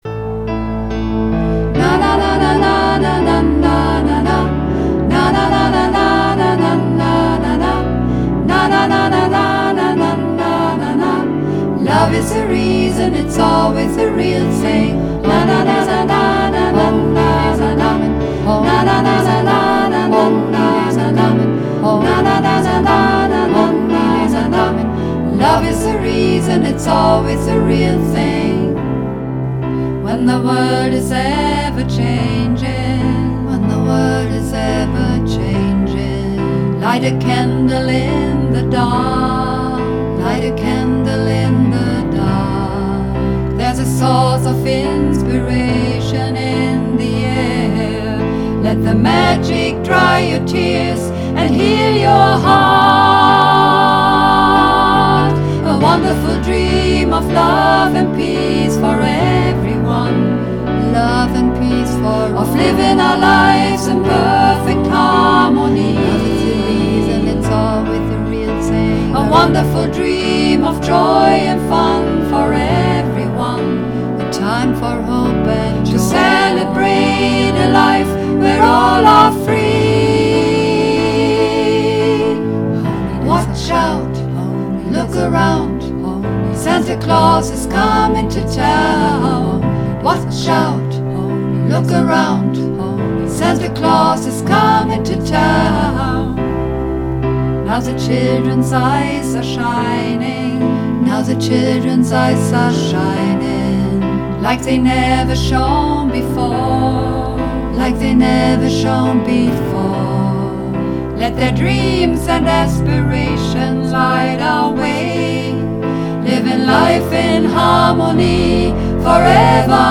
Übungsaufnahmen - Wonderful Dream
Runterladen (Mit rechter Maustaste anklicken, Menübefehl auswählen)   Wonderful Dream (Mehrstimmig)
Wonderful_Dream__5_Mehrstimmig.mp3